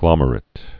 (glŏmər-ĭt)